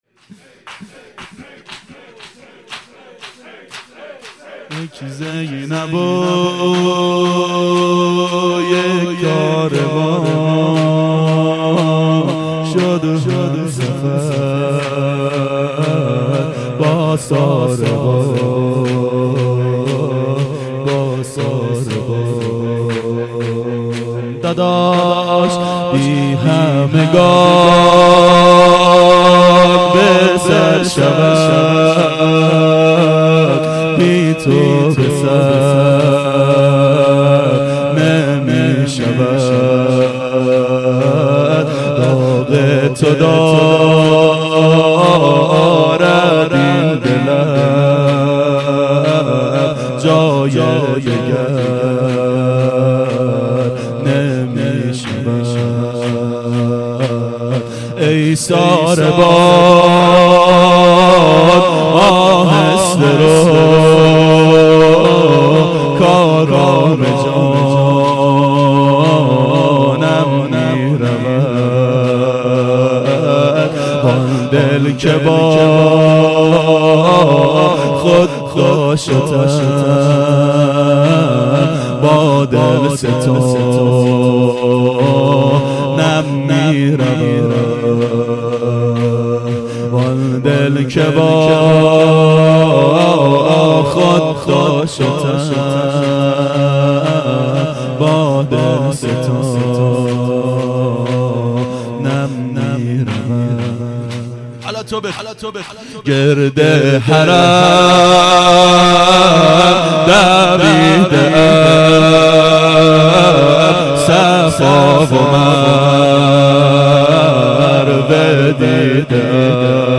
ظهر اربعین 1391 هیئت شیفتگان حضرت رقیه سلام الله علیها